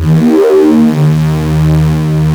OSCAR 10 D#2.wav